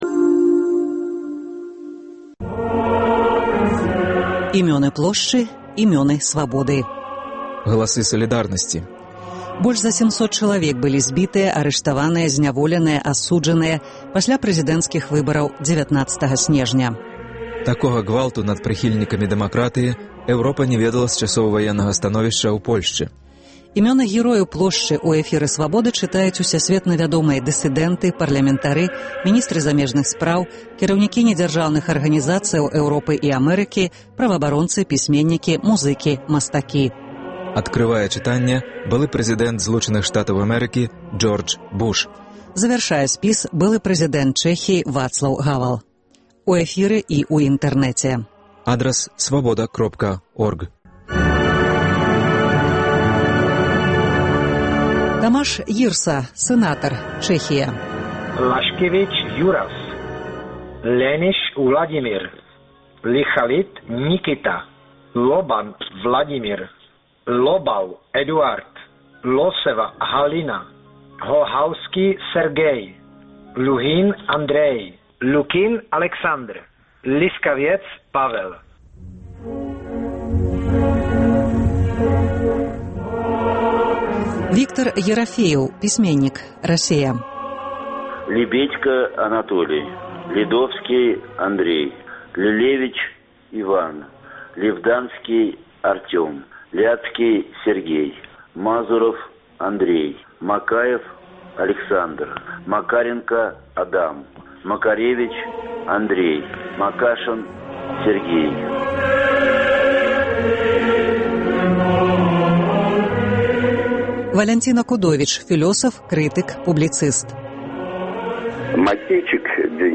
Імёны герояў Плошчы ў эфіры Свабоды чытаюць усясьветна вядомыя дысыдэнты, парлямэнтары, міністры замежных справаў, кіраўнікі недзяржаўных арганізацыяў Эўропы і Амэрыкі, праваабаронцы, пісьменьнікі, музыкі, мастакі. Адкрывае чытаньне былы прэзыдэнт Злучаных Штатаў Джордж Буш. Завяршае былы прэзыдэнт Чэхіі Вацлаў Гавэл.